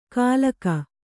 ♪ kālaka